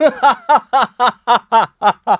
Riheet-Vox_Happy3.wav